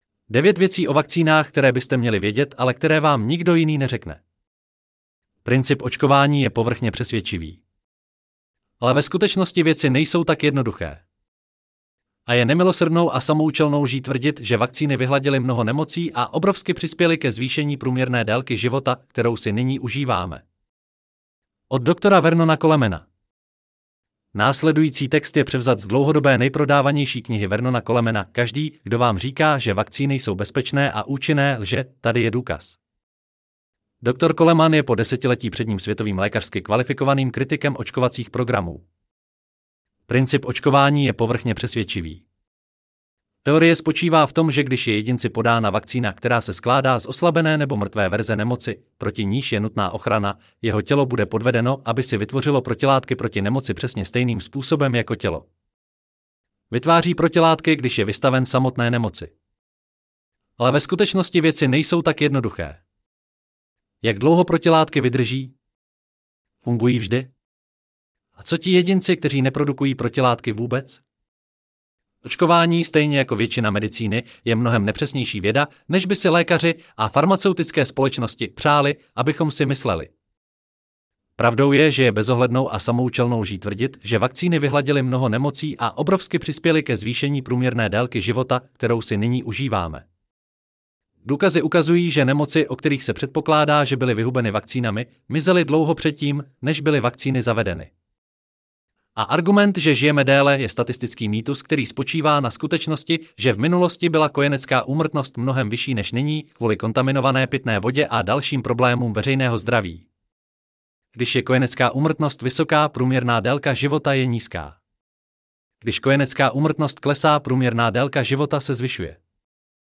Celý článek si můžete poslechnout v audio verzi zde.: